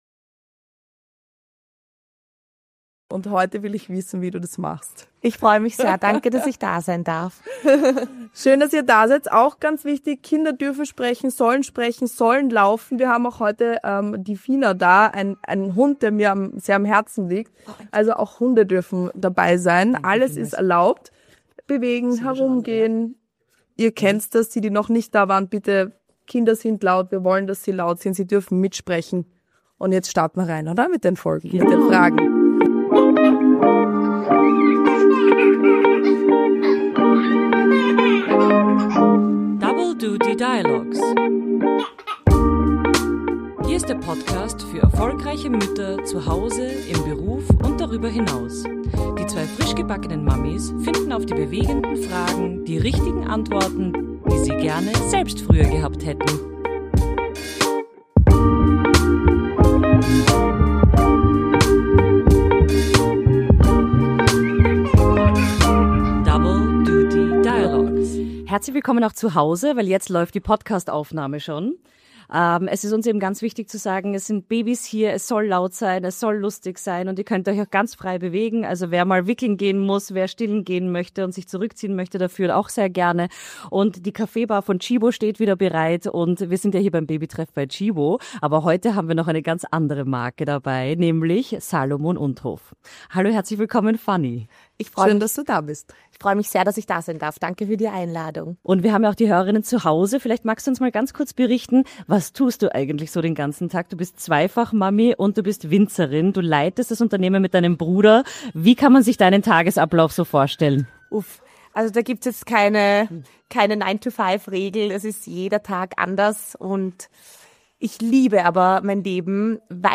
Wir 3 und das Publikum, das auch anschließend noch eifrig geplaudert hat, waren uns einig: Wir werden in unseren Berufen noch besser und fokussierter, weil wir Kinder haben nicht trotzdem.